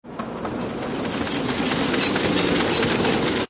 SZ_trolley_away.ogg